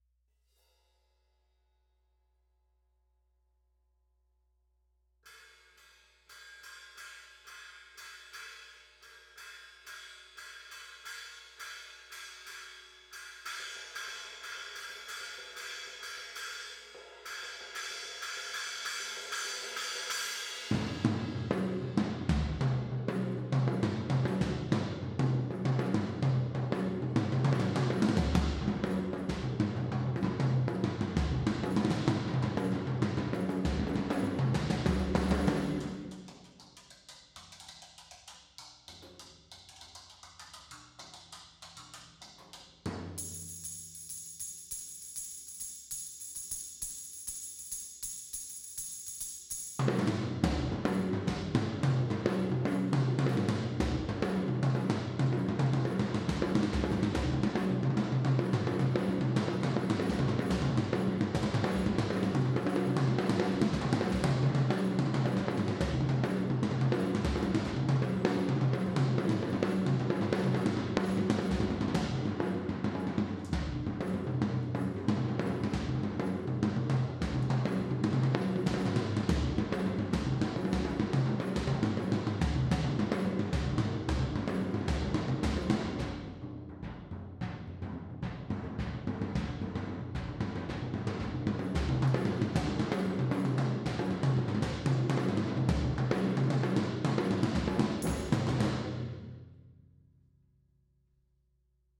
Percussion Improv